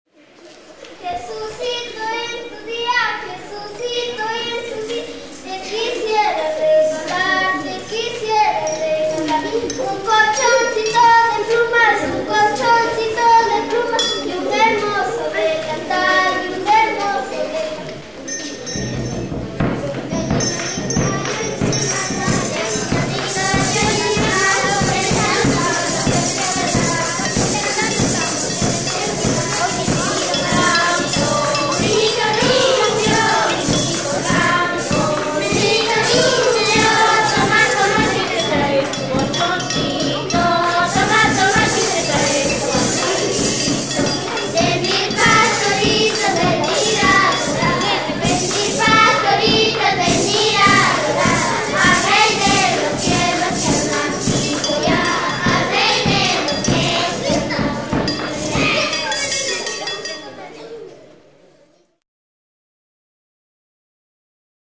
Producción sonora que presenta una selección de registros de expresiones musicales tradicionales vinculadas a la celebración de la Navidad en diversas regiones del Perú.
Cancionero, Canciones navideñas, Villancicos en quechua, Villancicos en español